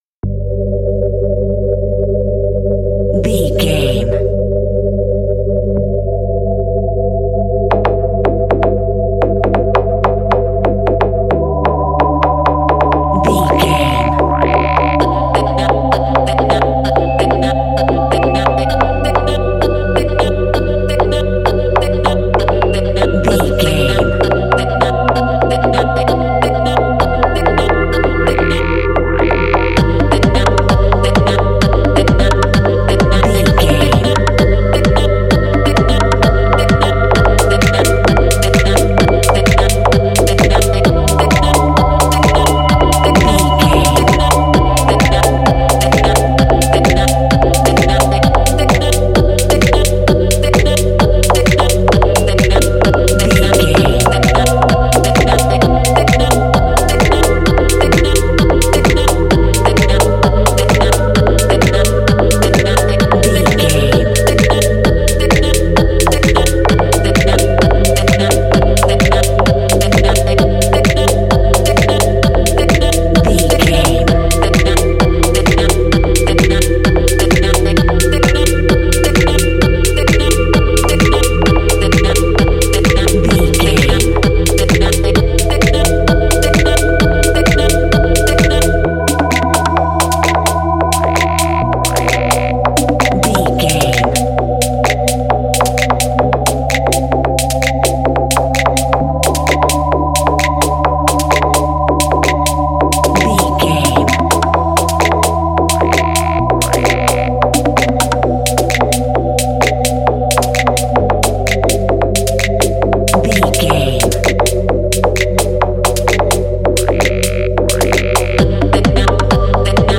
Ionian/Major
Fast
techno
hypnotic
atmospheric
dark
ambient
eerie
trance-inducing